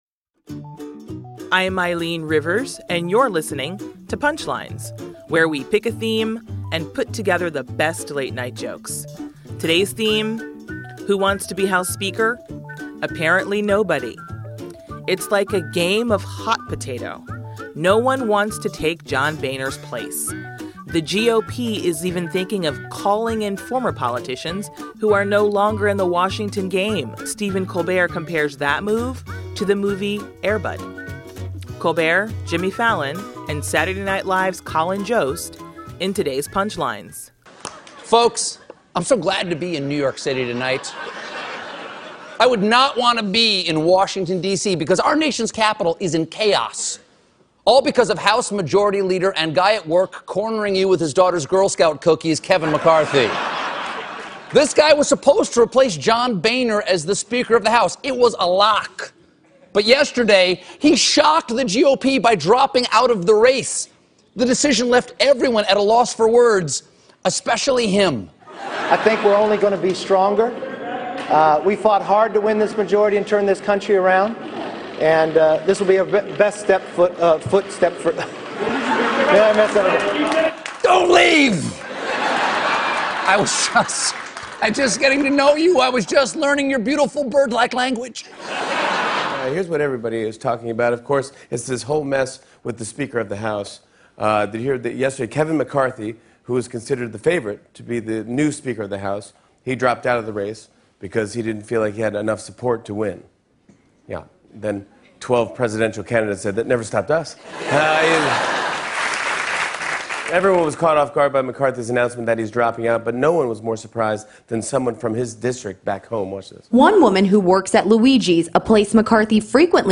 The late-night comics on the abrupt bye from Kevin McCarthy and the lack of interest in the position.